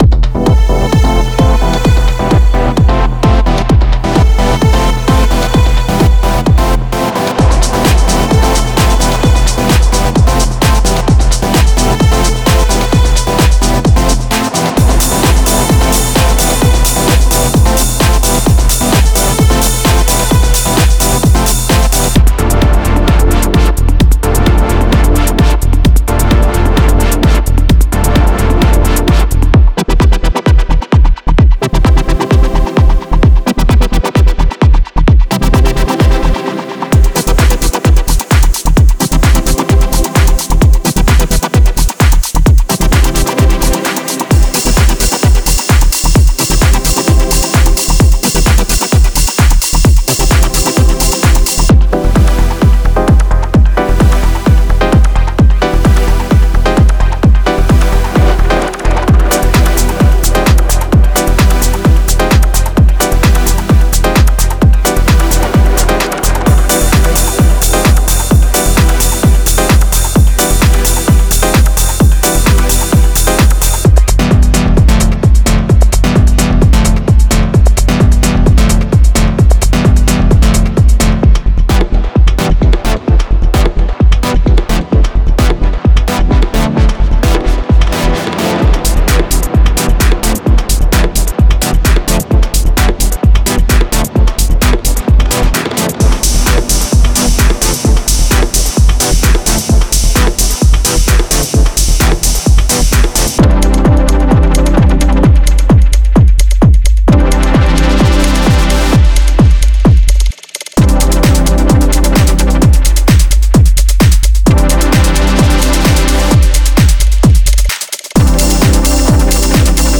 Genre:Dub Techno
95 Drum loops (Full, Kick, Clap, Hihat, Perc, Ride)